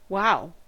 wow: Wikimedia Commons US English Pronunciations
En-us-wow.WAV